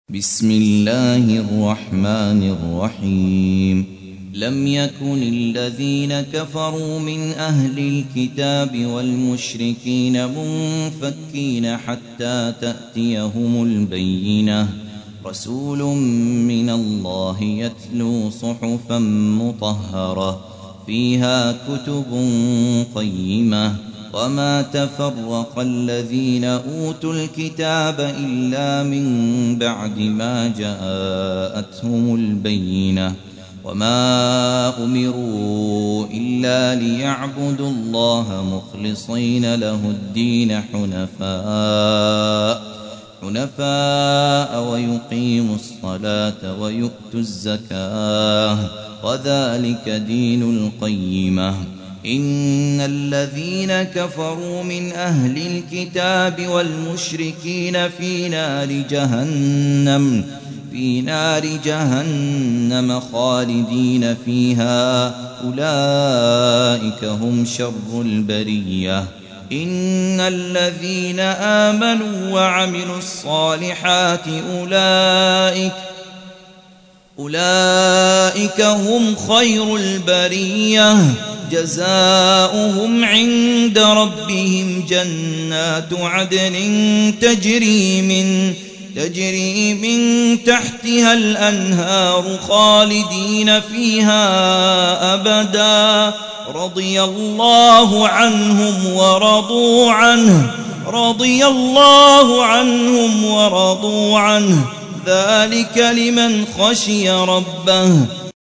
98. سورة البينة / القارئ